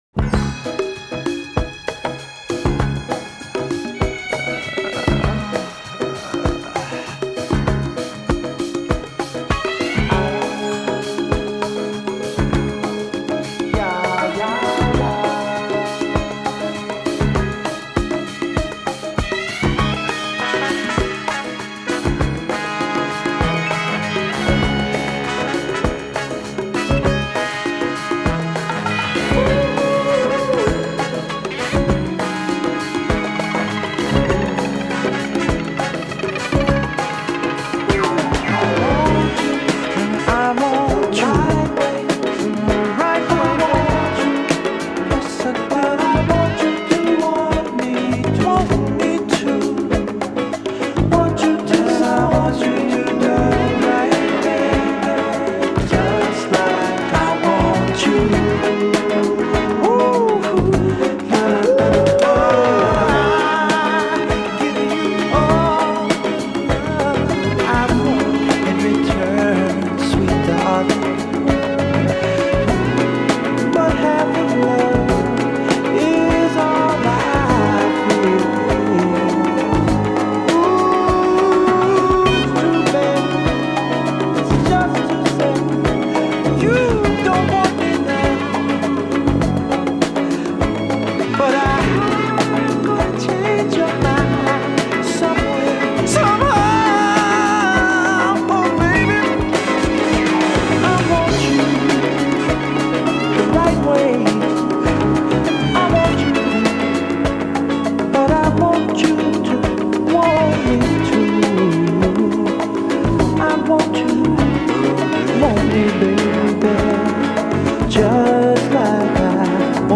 a caramel-smooth crooner with perfect pitch and enunciation